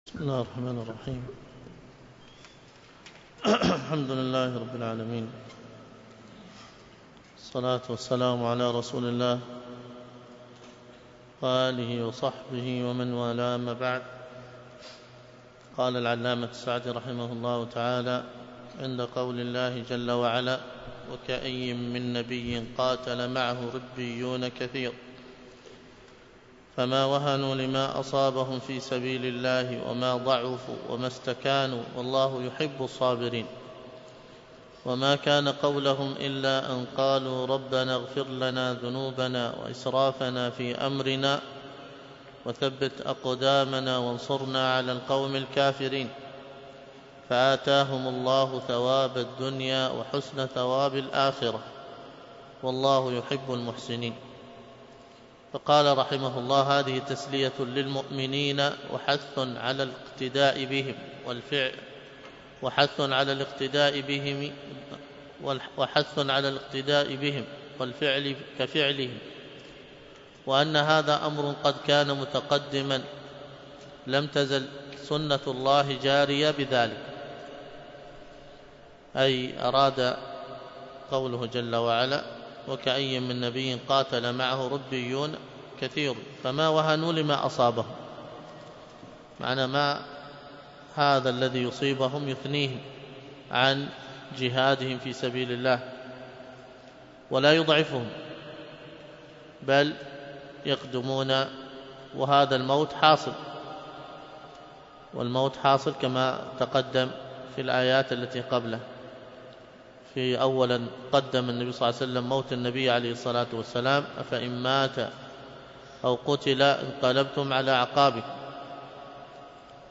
الدرس في تفسير سورة آل عمران من تفسير السعدي 84